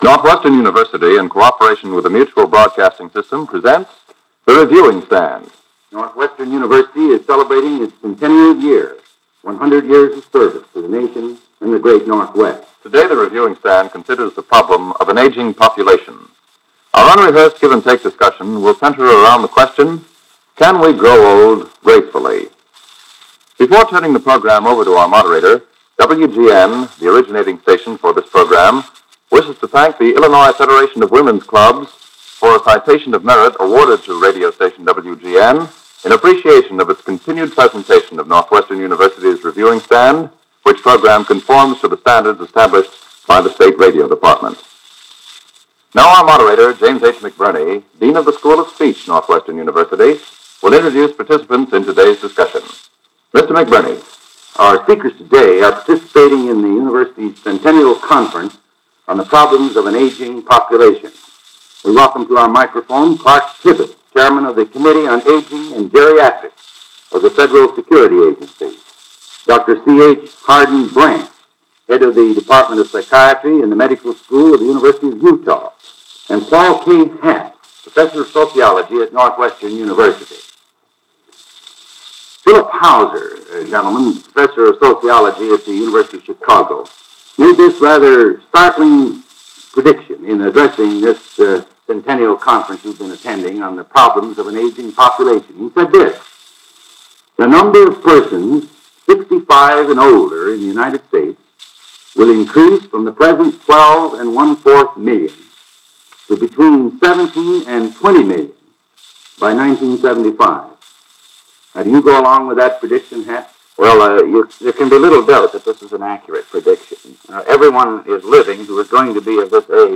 Can Americans Grow Old Gracefully? 1951 - Past Daily Reference Room - discussion on aging - Northwestern Reviewing Stand - July 20, 1951